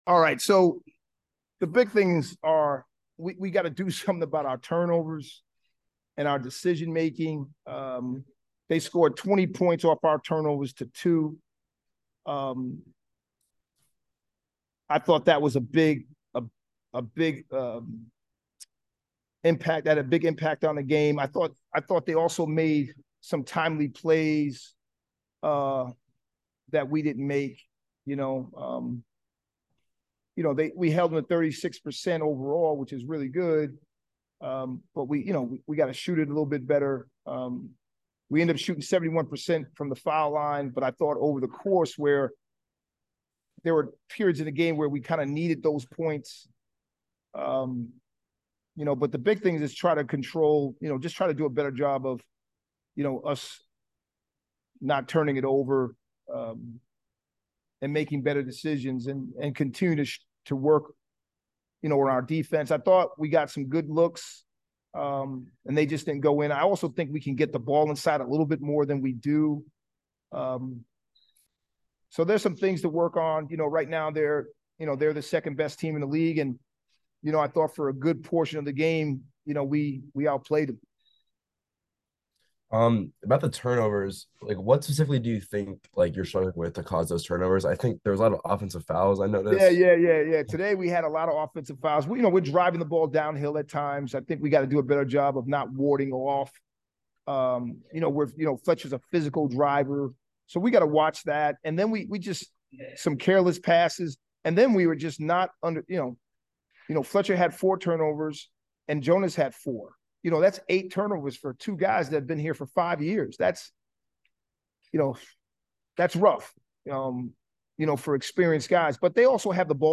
Men's Basketball / Lehigh Postgame Interview (1-29-23)